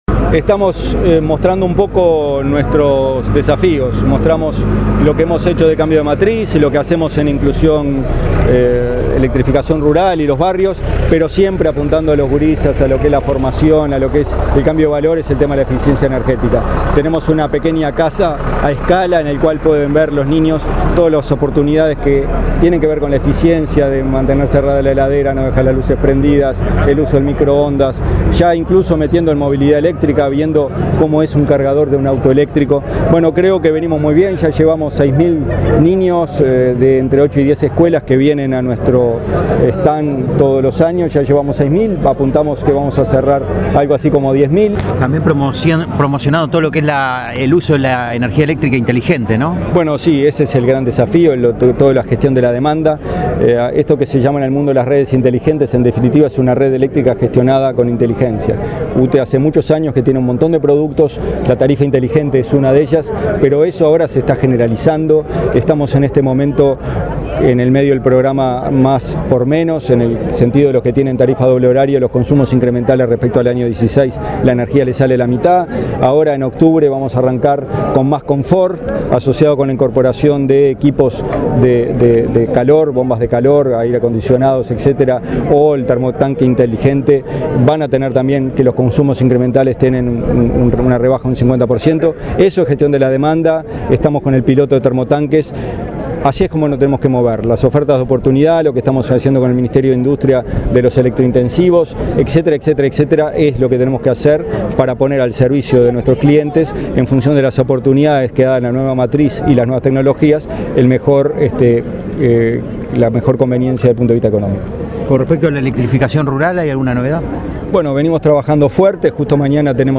En el marco de gestión de la demanda, UTE está lanzando en lo que queda del año algunos programas que promueven la compra de equipos eficientes, en función de la nueva matriz eléctrica. El ente inauguró su estand en la Expo Prado, oportunidad en la que su presidente, Gonzalo Casaravilla, habló de programas como Más por Menos, Más Confort, Ofertas de Oportunidades, que benefician a los clientes con reducción tarifaria.